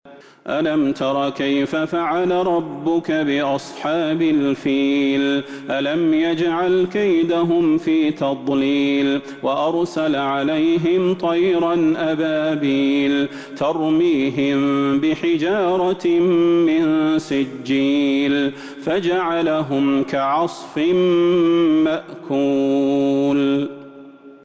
سورة الفيل Surat Al-Feel من تراويح المسجد النبوي 1442هـ > مصحف تراويح الحرم النبوي عام ١٤٤٢ > المصحف - تلاوات الحرمين